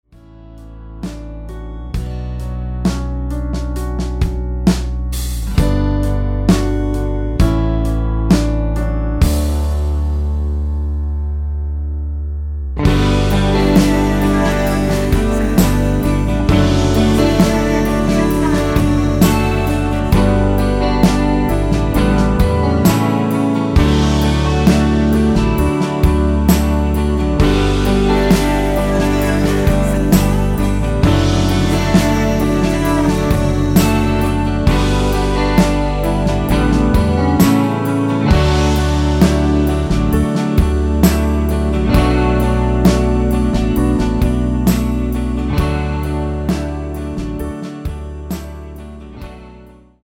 원키 코러스 포함된 MR입니다.
가사의 노란색 부분에 코러스가 들어갔습니다.(가사및 미리듣기 참조)
앞부분30초, 뒷부분30초씩 편집해서 올려 드리고 있습니다.
중간에 음이 끈어지고 다시 나오는 이유는